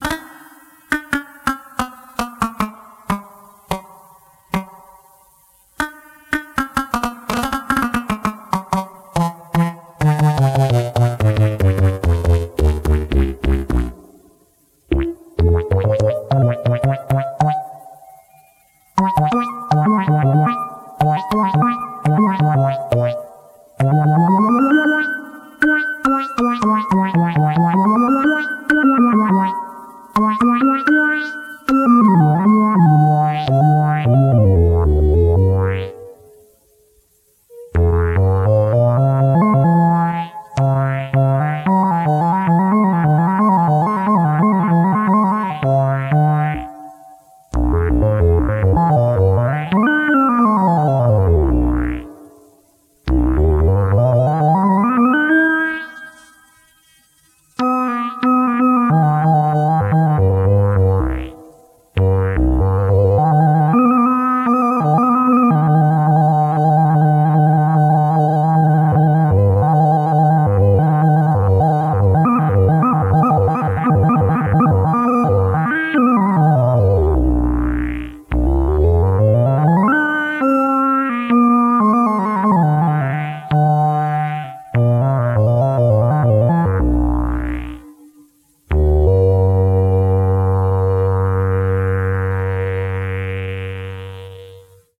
The complete set of my modified Synthi A TKS